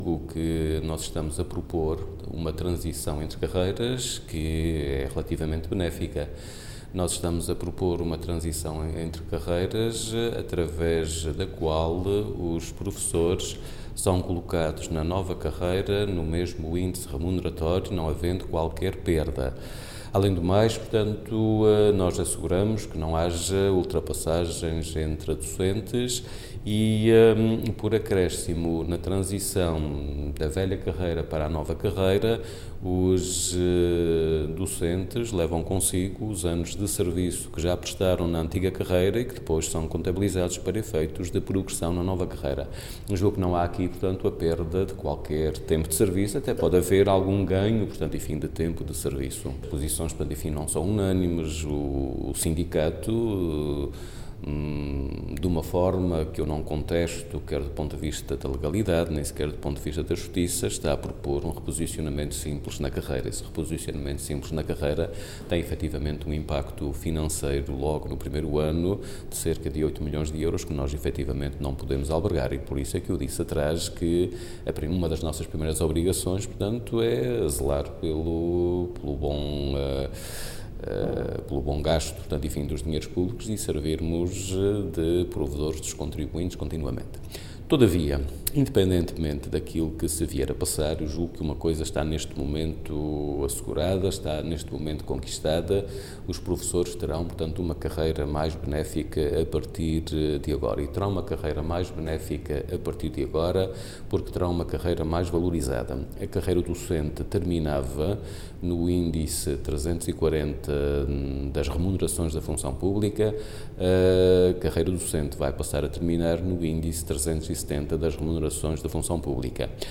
Avelino Meneses, que falava no final de uma reunião com o Sindicato Democrático dos Professores dos Açores, salientou que os docentes terão uma carreira “mais benéfica, porque terão uma carreira mais valorizada”.